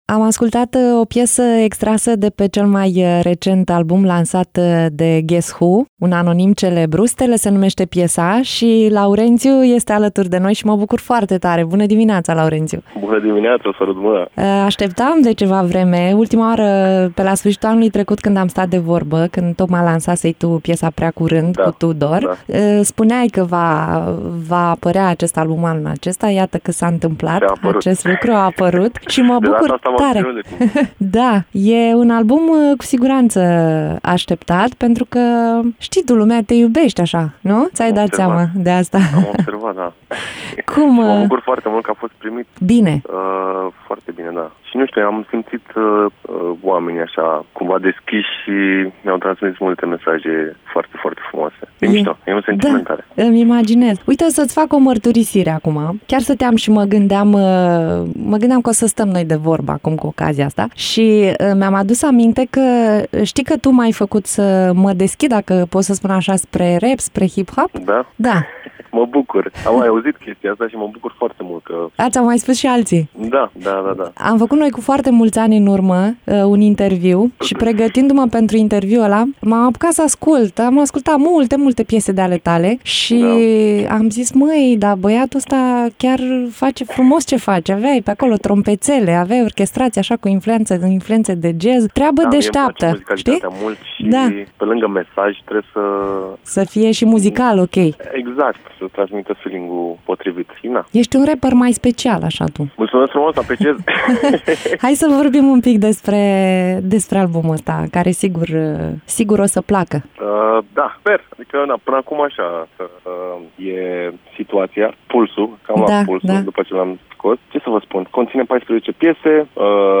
interviu-guess-who.mp3